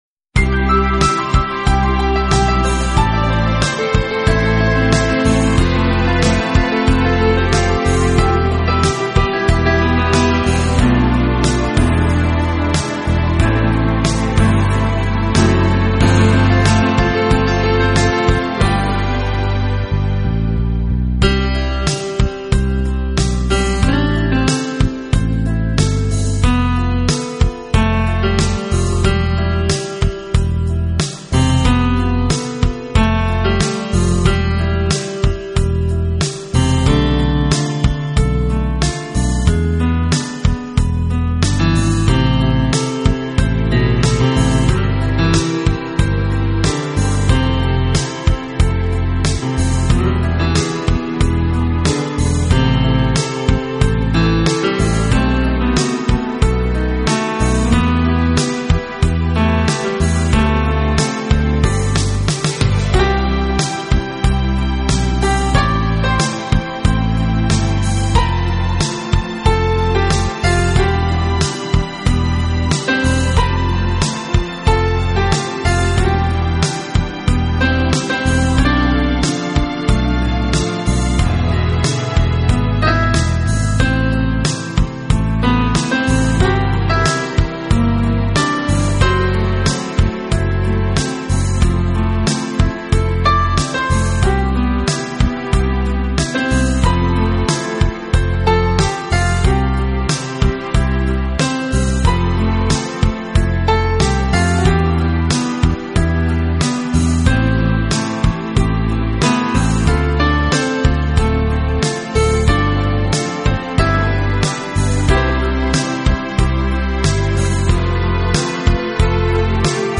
Country / Pop / Classic
本套2CD全部钢琴演奏，